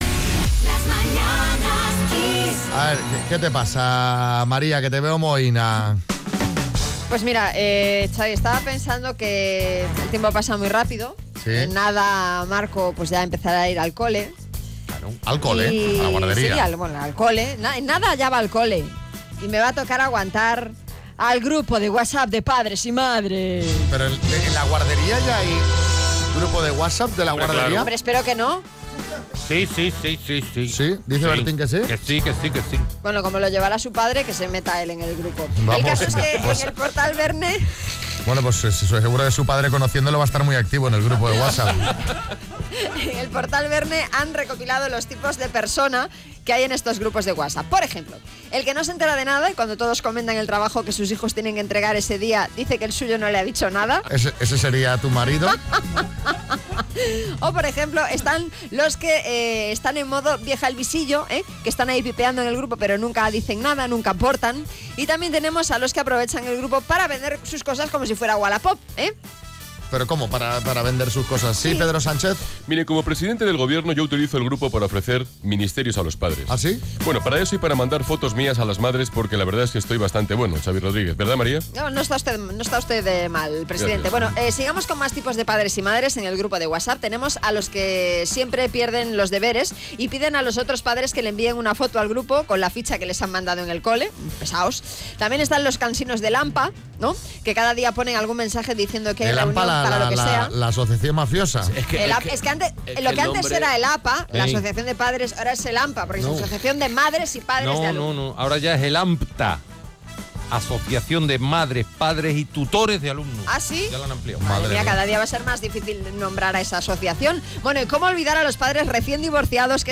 Los oyentes nos cuentan cómo llevan eso de formar parte del grupo de padres de Whatsapp.